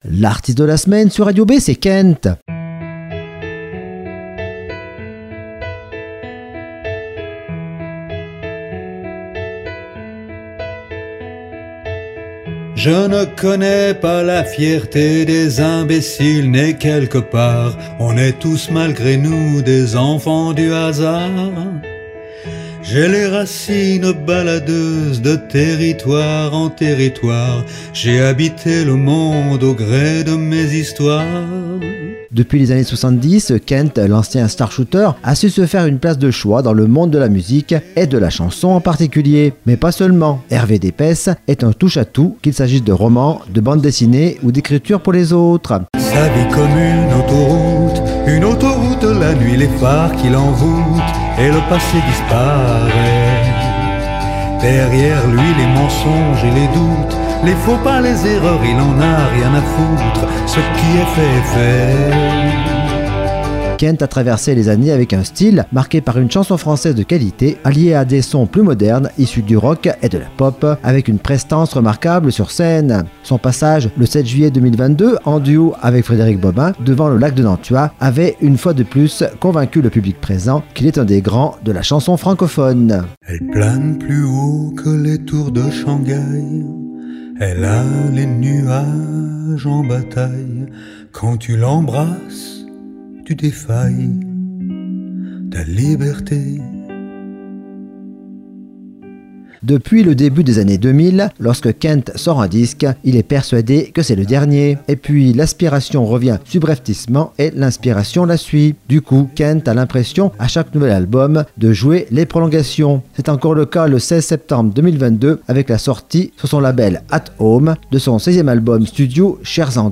KENT a traversé les années avec un style, marqué par une chanson française de qualité alliée à des sons plus modernes issus du rock et de la pop avec une prestance remarquable sur scène.
KENT, une authenticité dans le verbe et dans la voix.